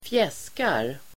Ladda ner uttalet
fjäska verb, fawn on , suck up to Grammatikkommentar: A & (för B) Uttal: [²fj'es:kar] Böjningar: fjäskade, fjäskat, fjäska, fjäskar Synonymer: lisma, rövslicka, smöra Definition: krusa, smickra, ställa sig in